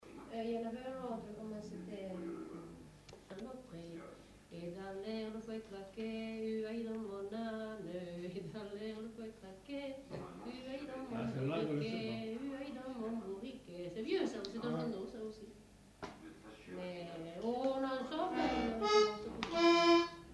Aire culturelle : Savès
Genre : chant
Effectif : 1
Type de voix : voix de femme
Production du son : chanté
Danse : rondeau
Notes consultables : Le début du chant n'est pas enregistré.